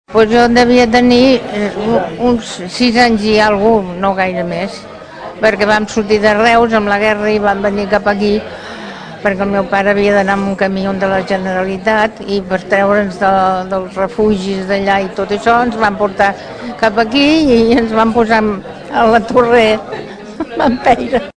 Així d’emocionada ens explicava, dissabte, el que havia sentit en veure de nou la casa i com recordava tot de detalls.